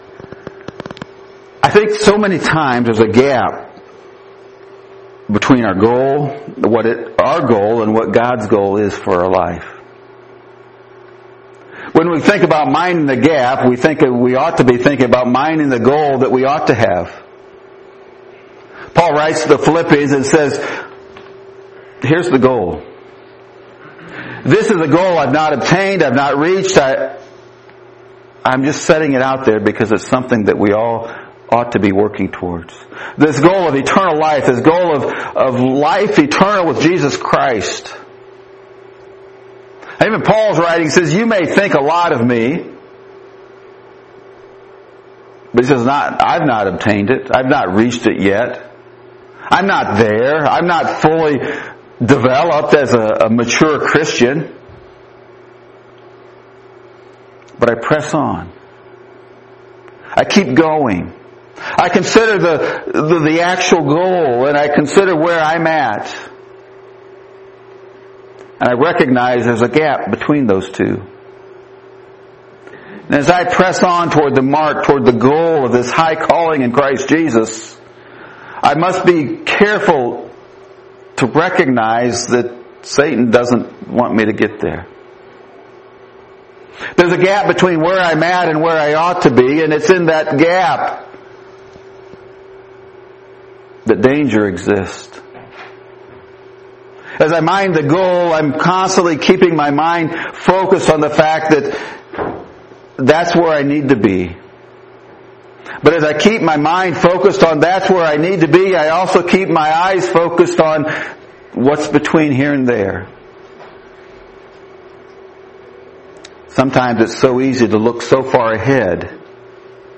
(I didn’t remember to turn the recorder on until after the sermon introduction, but this audio contains all three main points.)